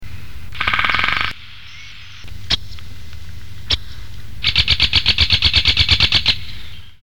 Pic épeiche
pic-epeiche.mp3